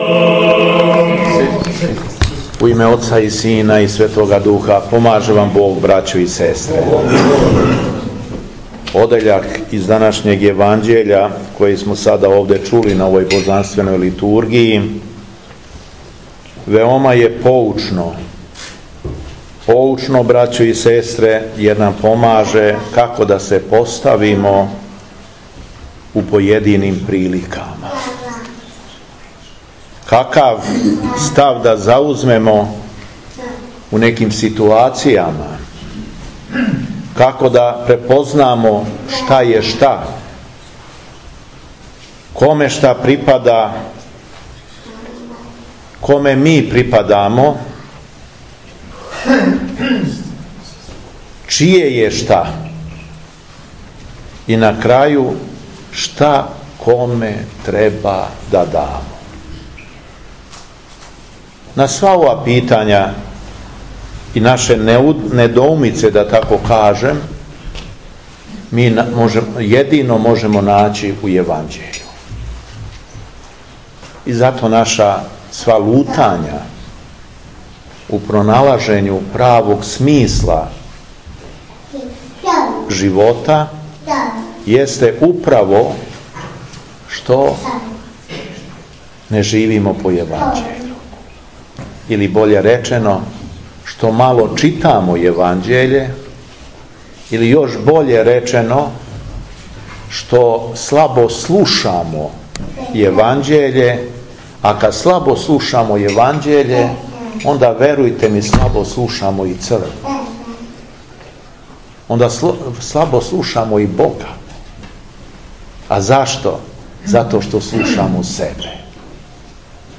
Дана 10. септембра 2022. год. када наша Света Црква прославља и празнује Преподобног Мојсеја Мурина и Преподобног Саву Псковског, Његово Преосвештенство Епископ шумадијски г. Јован служио је Свету Архијерејску Литургију у цркви Преподобног Симеона Столпника у Дубони код Младеновца.
Беседа Његовог Преосвештенства Епископа шумадијског г. Јована